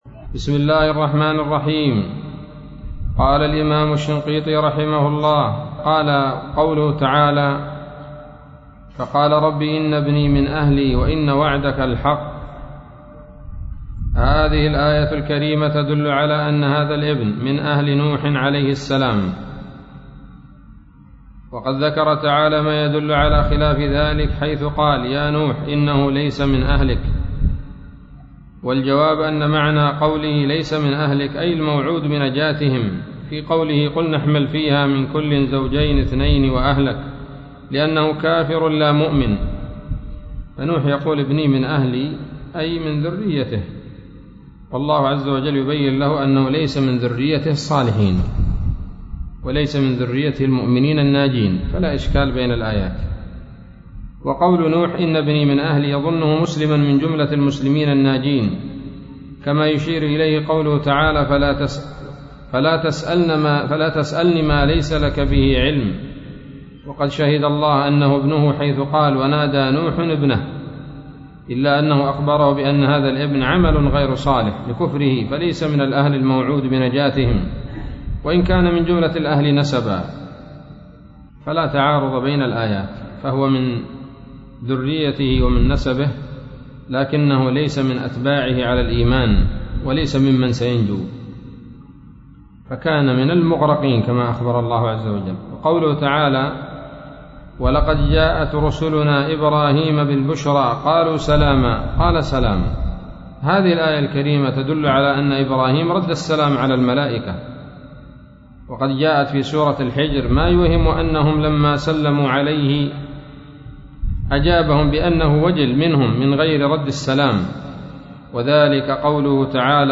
الدرس الخمسون من دفع إيهام الاضطراب عن آيات الكتاب